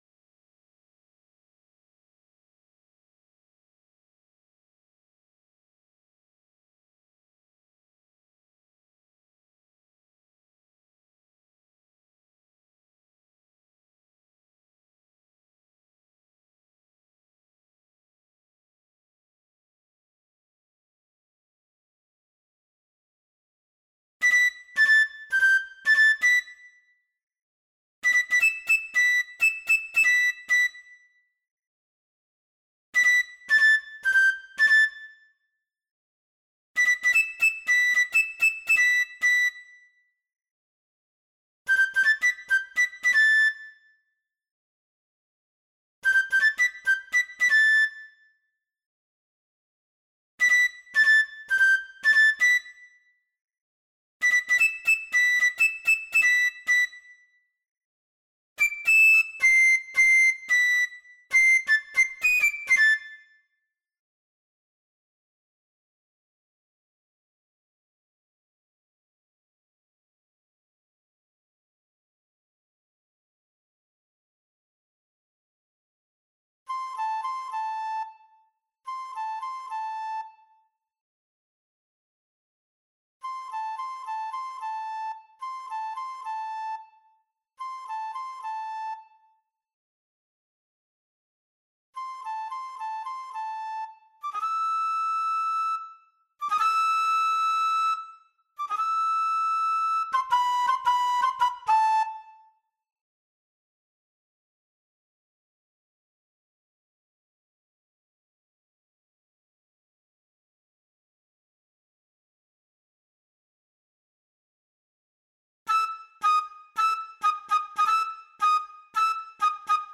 Sing3-v1-Fl1.mp3